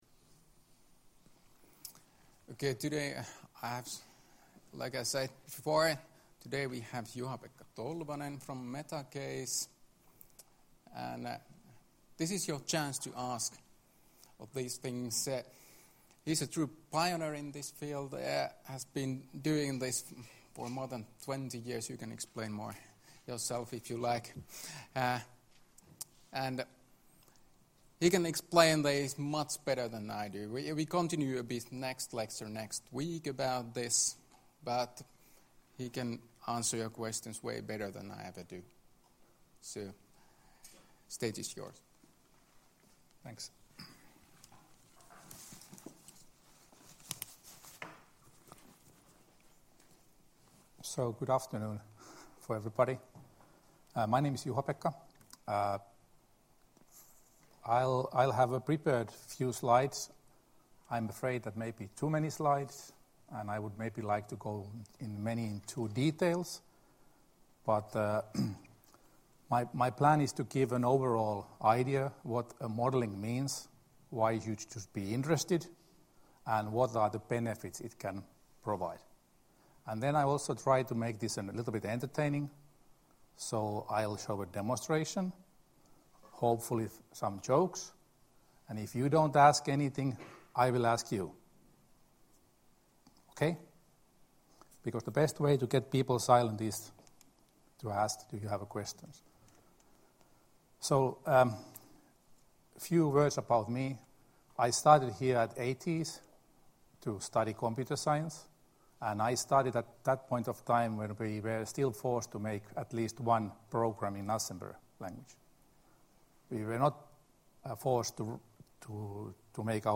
Luento 8.2.2018 — Moniviestin